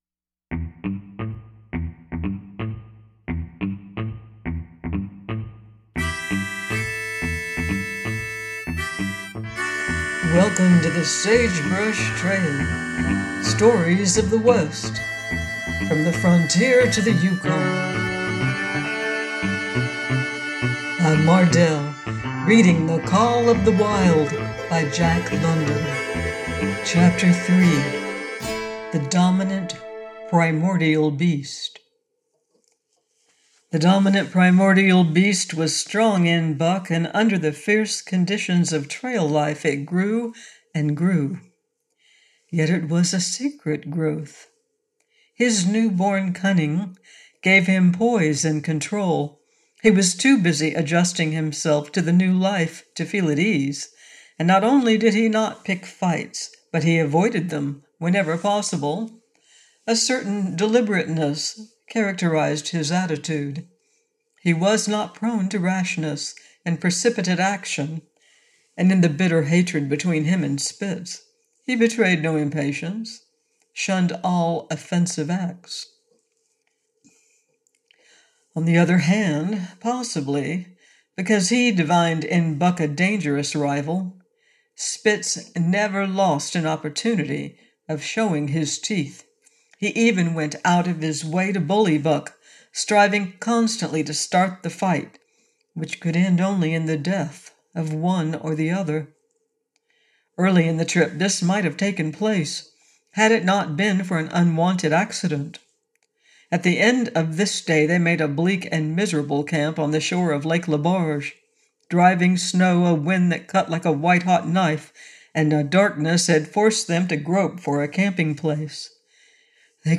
The Call Of The Wild: by Jack London - AUDIOBOOK